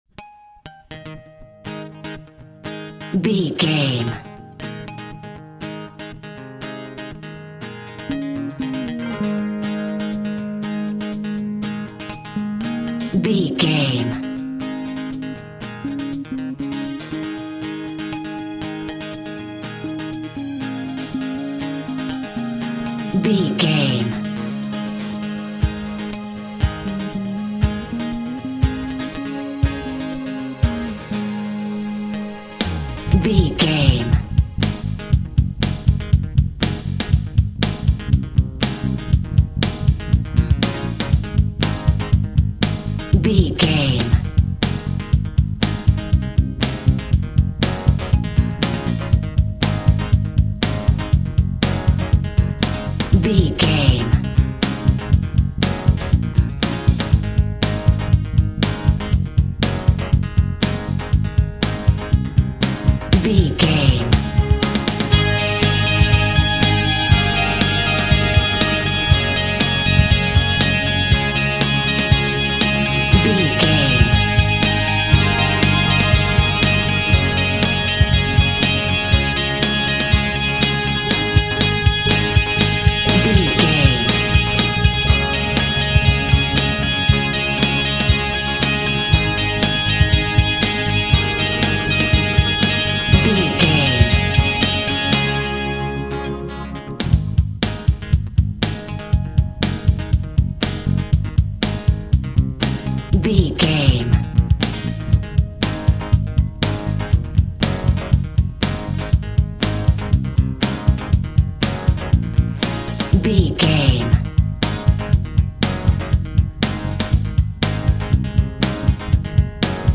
Boy Band Rock.
Uplifting
Ionian/Major
A♭
cheesy
bubblegum
drums
bass guitar
electric guitar
piano
hammond organ